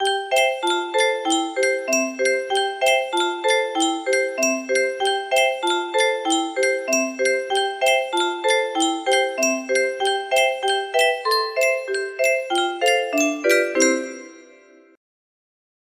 Yunsheng Spieluhr - Hoppe Hoppe Reiter 2339 music box melody
Full range 60